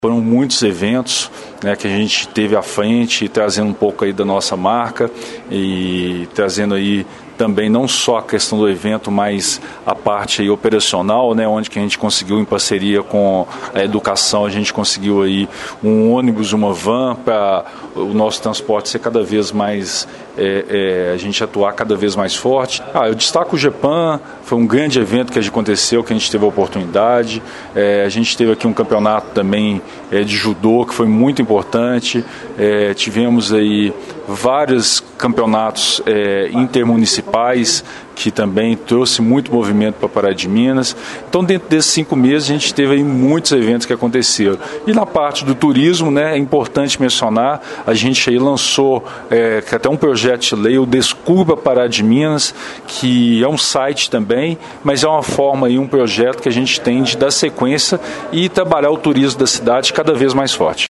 Em conversa com o JM, Helton Simão agradeceu pela confiança do prefeito Elias Diniz e o apoio dos demais secretários. Ele fez um rápido balanço de seu trabalho e destacou os projetos que considerou mais importantes nesses meses de secretário.